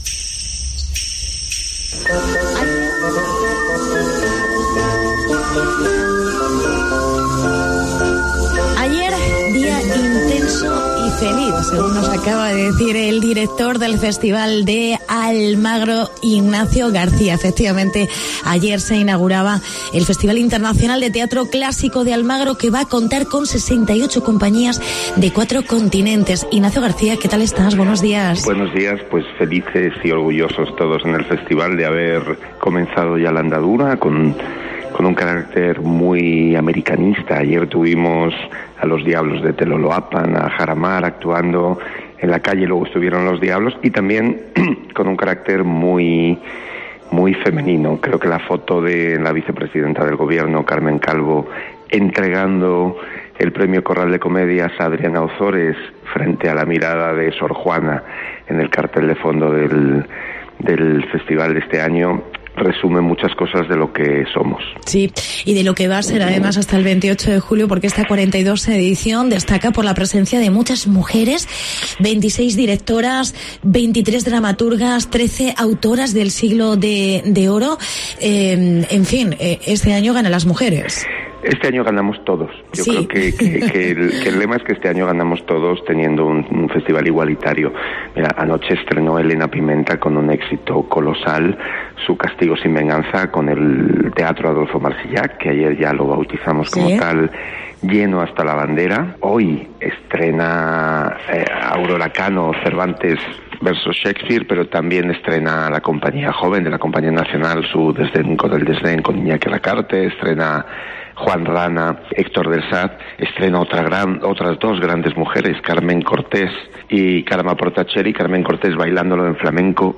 Comienza el 42º Festival de Almagro 2019. Entrevista